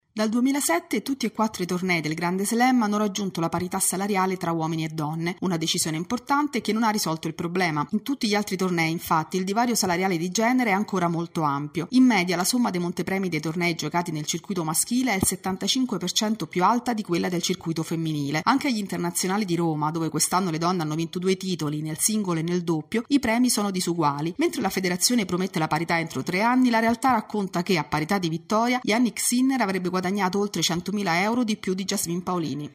Sport